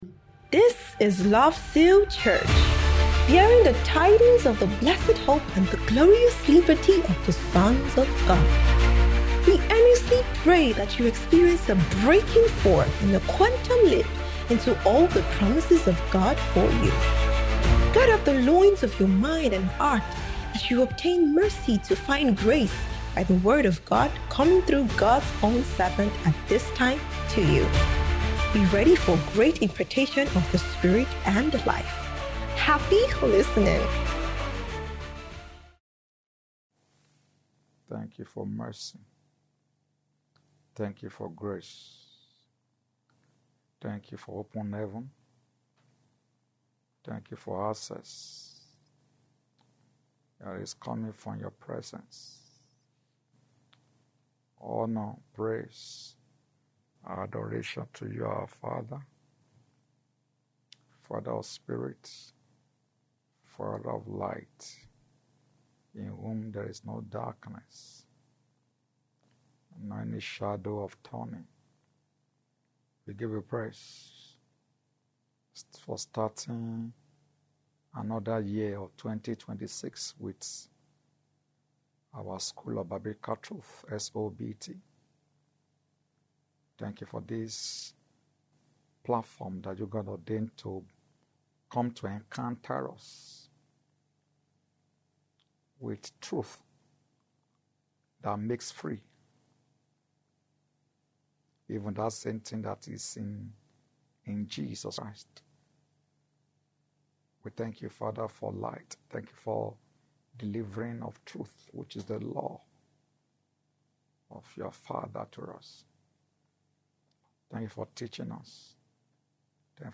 SOBT - SPECIAL MIDWEEK TEACHING SERIES